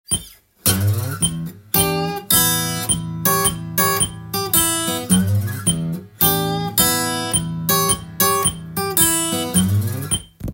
このように４分音符でメトロノームを鳴らして
裏打ちと言われる　２拍目と４拍目にメトロノームを鳴らし